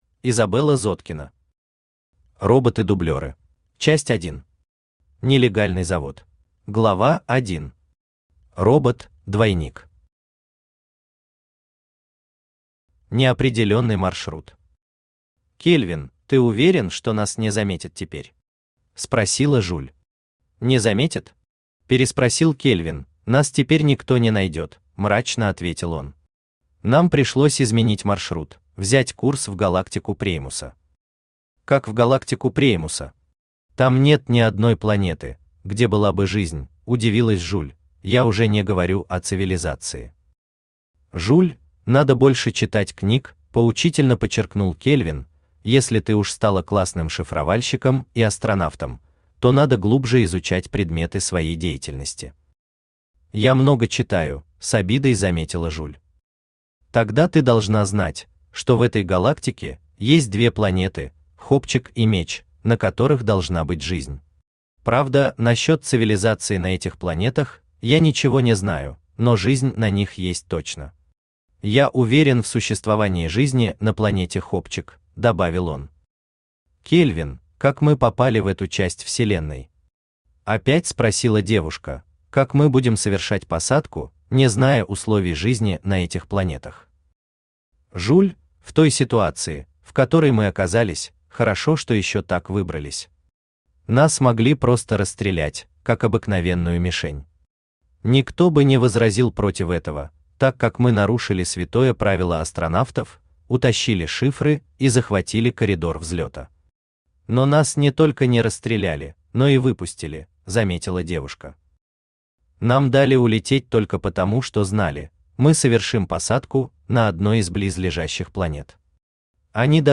Аудиокнига Роботы-дублёры | Библиотека аудиокниг
Aудиокнига Роботы-дублёры Автор Изабелла Зоткина Читает аудиокнигу Авточтец ЛитРес.